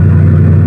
engine1.wav